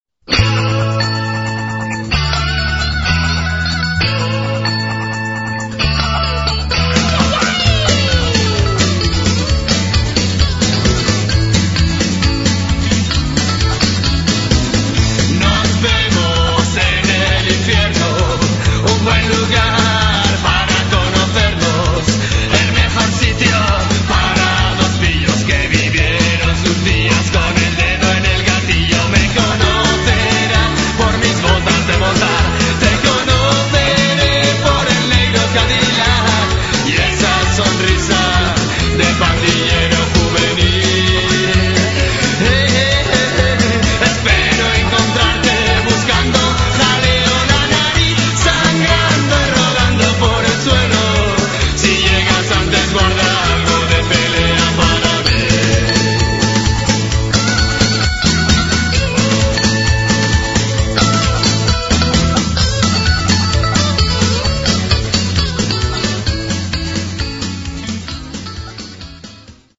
El aire es alegre y desenfadado.
Algunas canciones que se pueden bailar como polka:
polka country